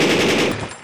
Various MG audio (wav)
machinegun1.wav
machinegun1_418.wav